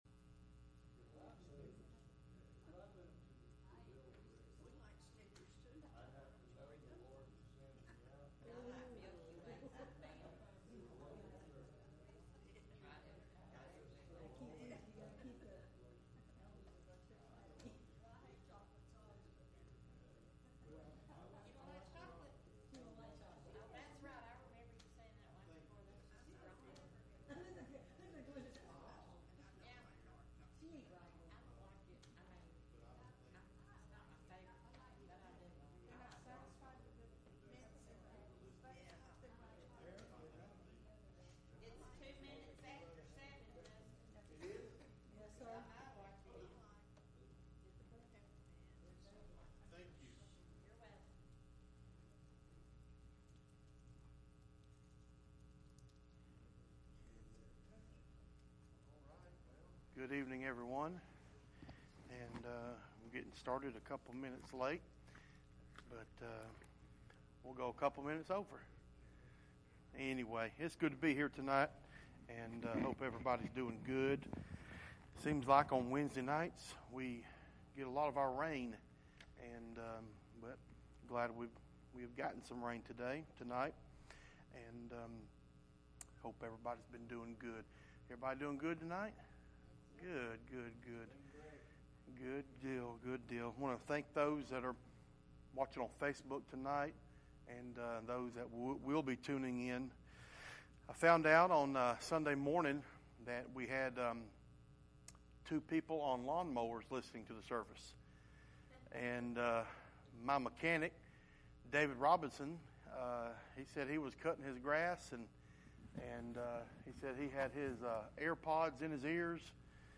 Psalm 100:1-5 Service Type: Midweek Meeting « God’s Lost And Found